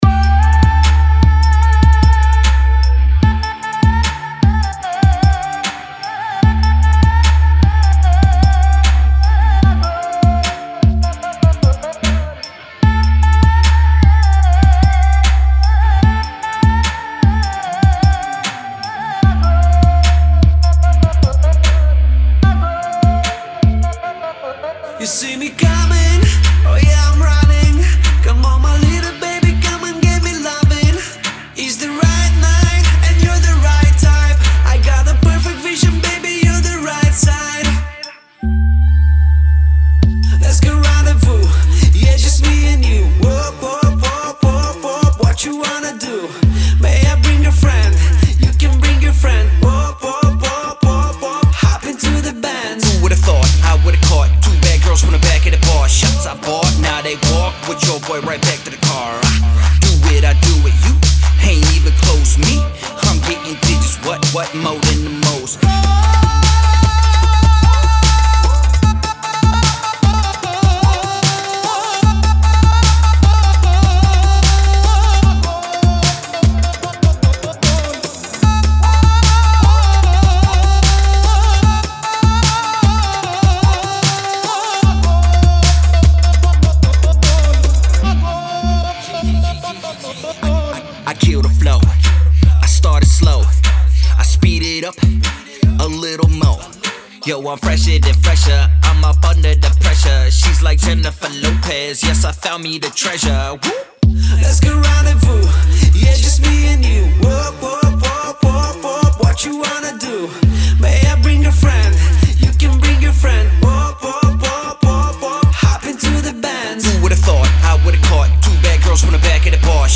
зажигательная композиция в жанре поп и EDM